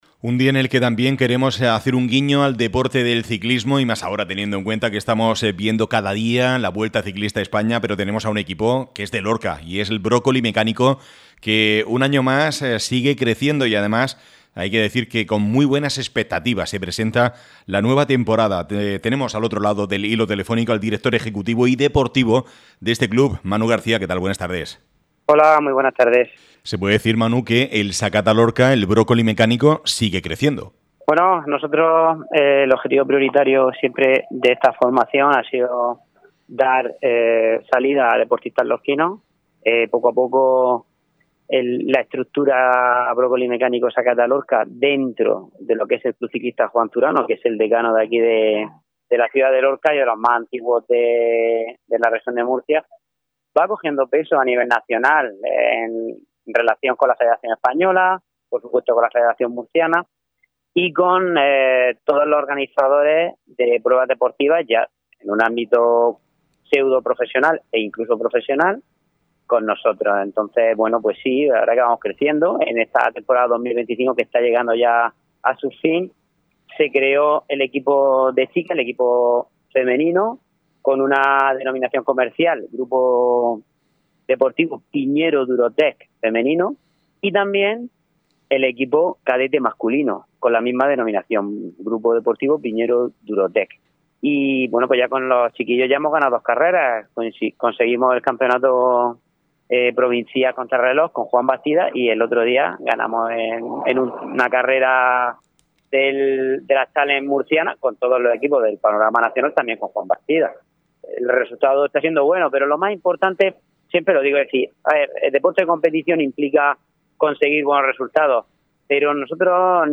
ÁREA LORCA RADIO. Deportes.